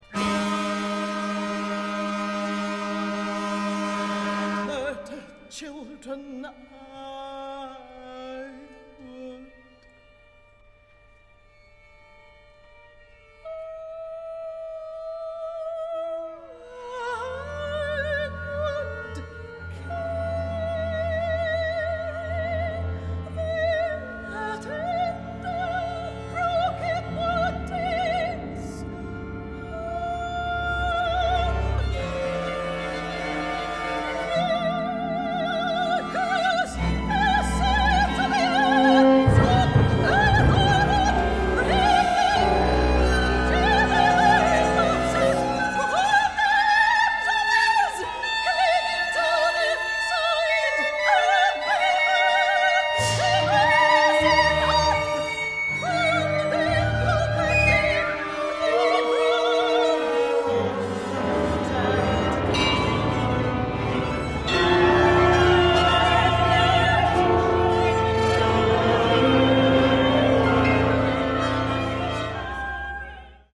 one act opera
Westdeutscher Rundfunk, Cologne, Germany